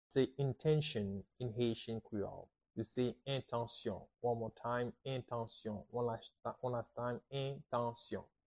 How to say "Intention" in Haitian Creole - "Entansyon" pronunciation by a native Haitian Tutor
“Entansyon” Pronunciation in Haitian Creole by a native Haitian can be heard in the audio here or in the video below: